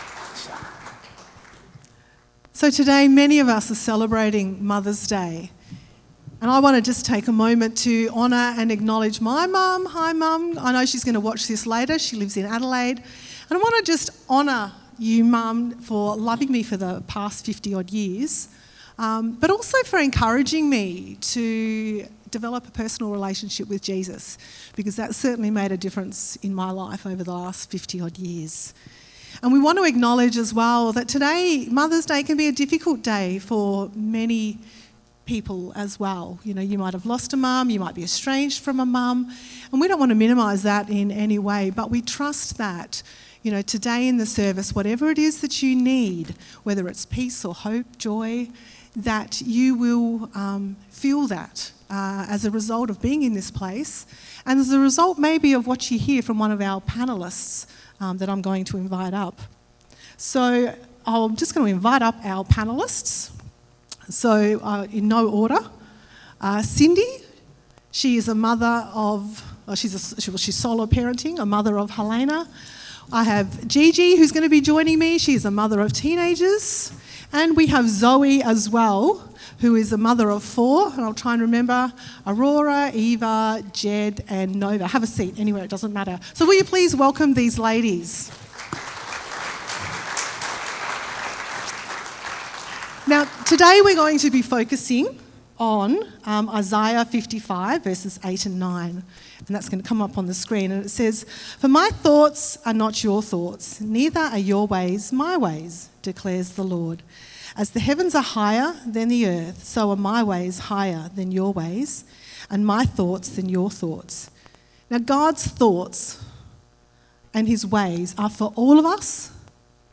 Isaiah 55:8-9 tells us that God’s way is higher than our way. Hear from 3 panelists sharing their experiences about God’s higher way to parent children.